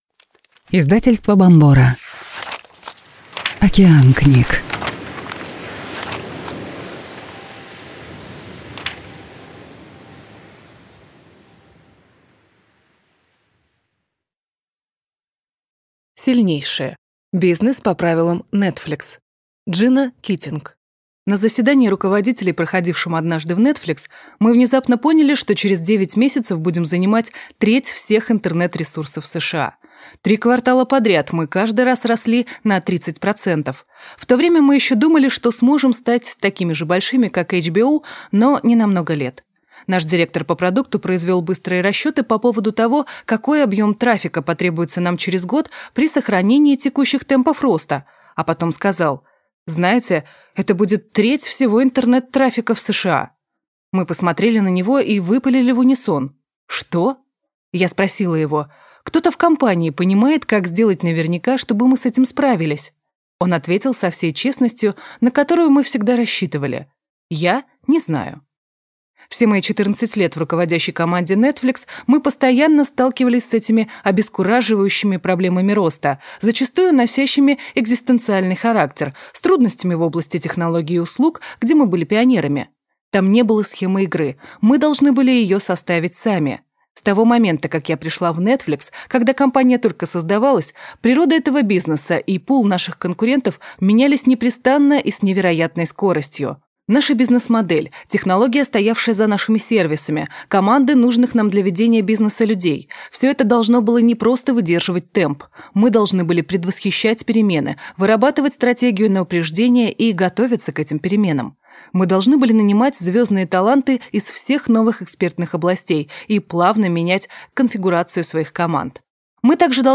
Аудиокнига Сильнейшие. Бизнес по правилам Netflix | Библиотека аудиокниг